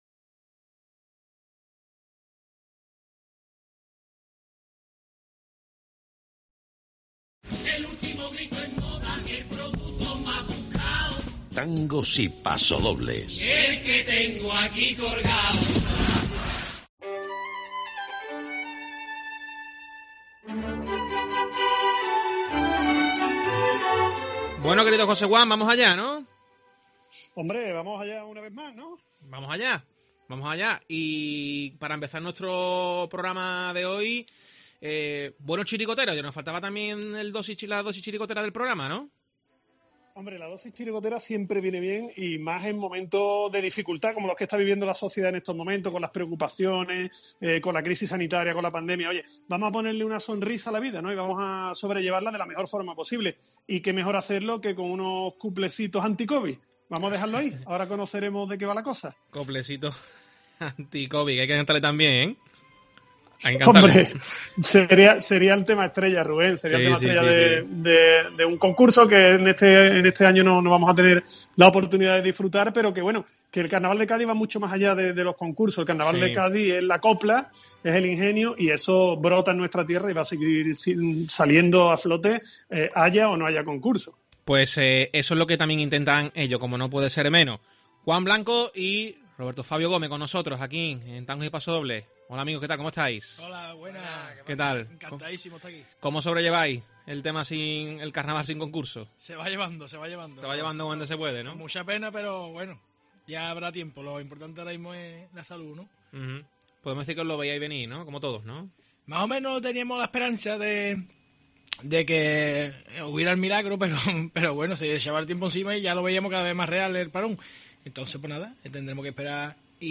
Cuplés anti COVID